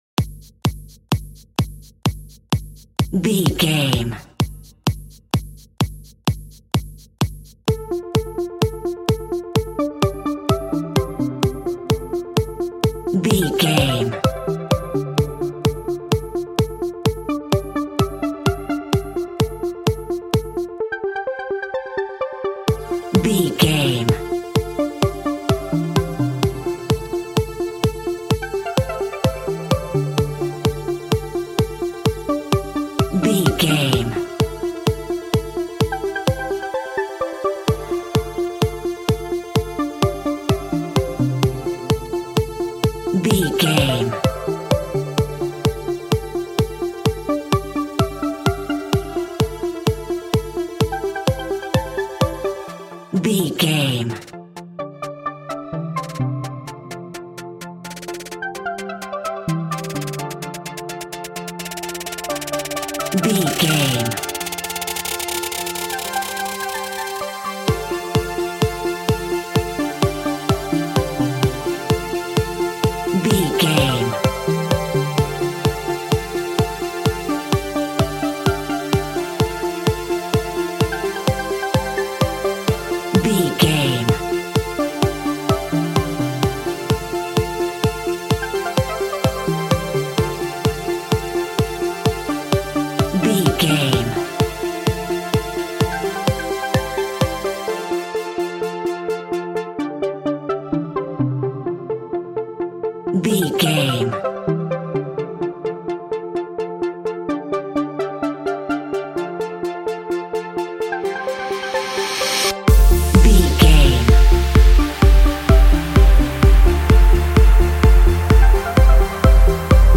Hard and Tight Trance.
Aeolian/Minor
energetic
hypnotic
drum machine
synthesiser
acid trance
instrumentals
synth leads
synth bass